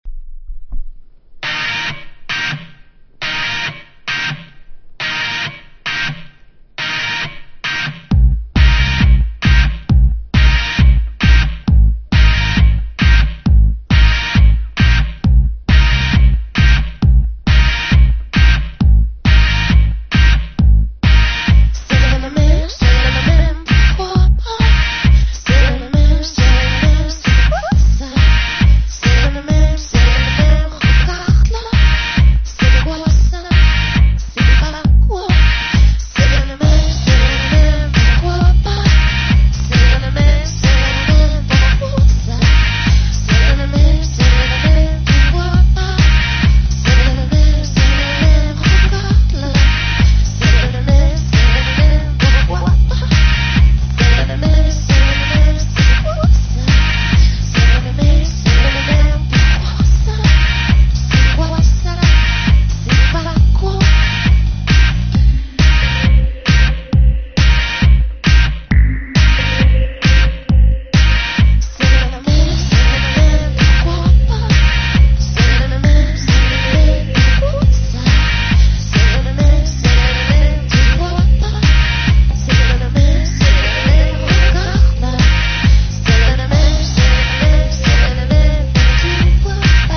# ELECTRO